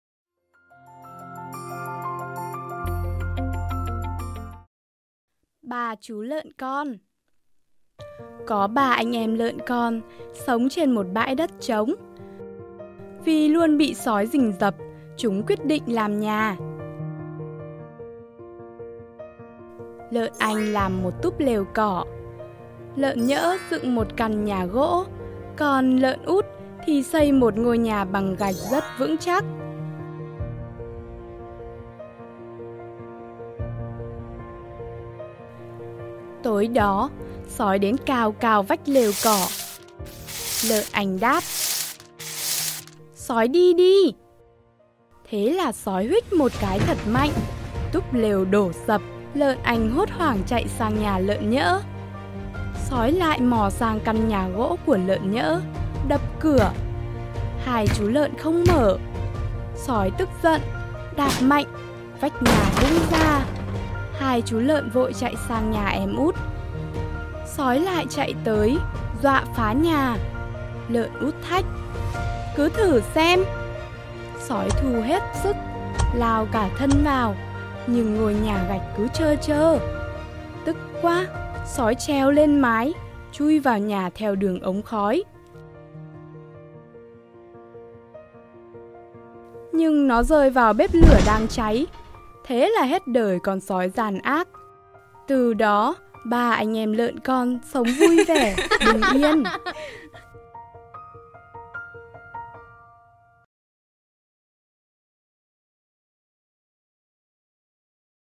Sách nói | Ba chú lợn con